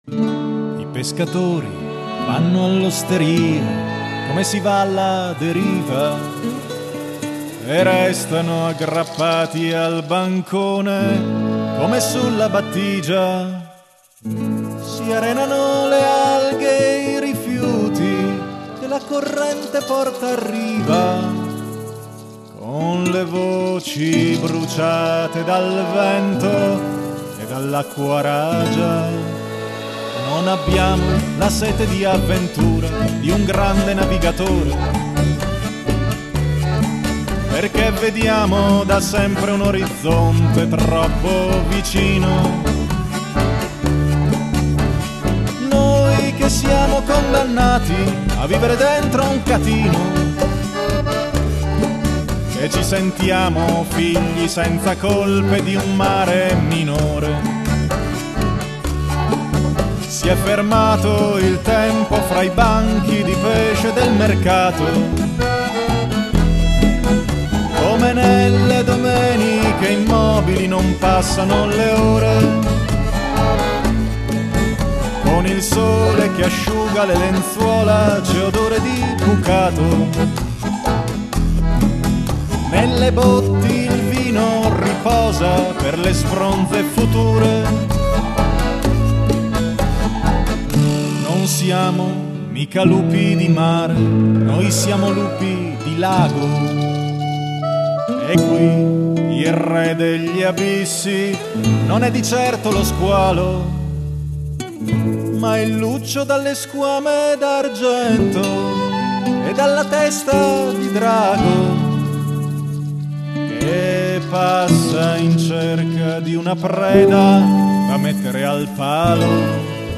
Luogo esecuzioneBologna
GenerePop / Musica Leggera